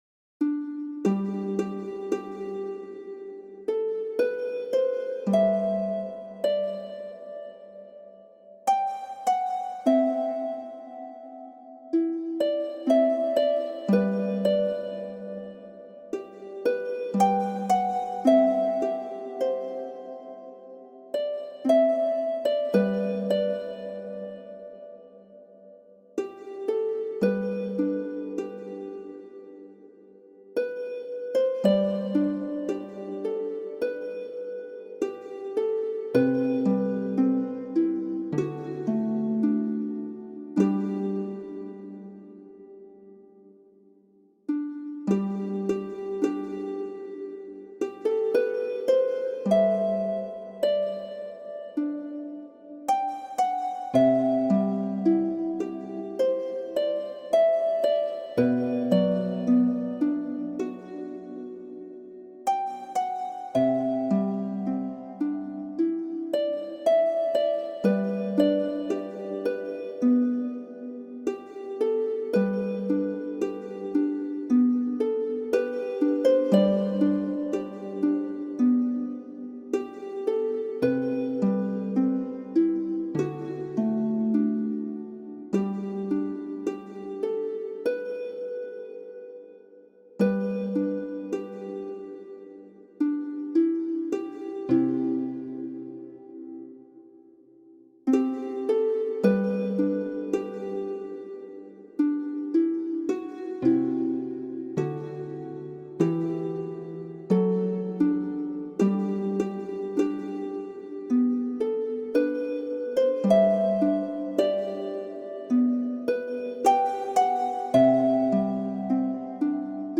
Shenandoah : musique de harpe relaxante traditionnelle